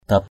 /d̪ap/ (d.) phía, hàng. rabaong dap gah kamei matuaw haniim (DN) r_b” dP gH km] mt&| hn`[ con mương phía bên phụ nữ thì tốt đẹp.
dap.mp3